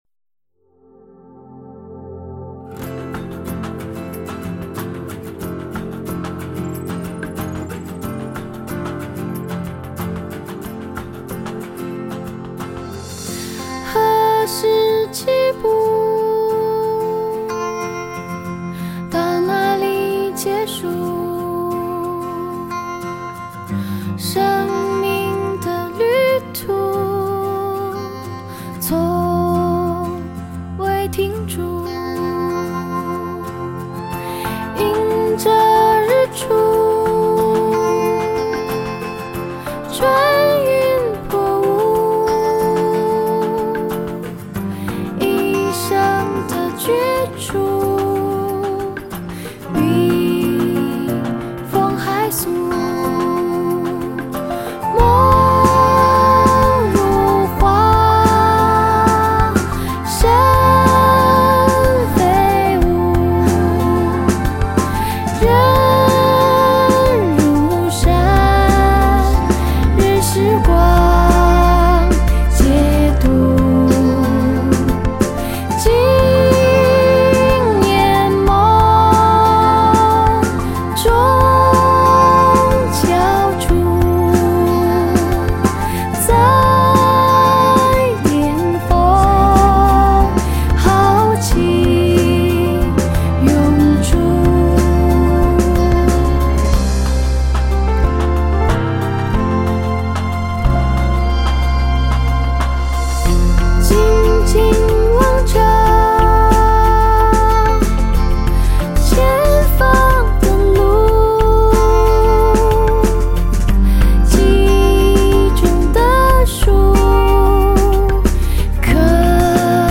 背景二胡音乐